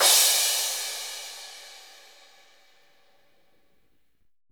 BRT CRASH.wav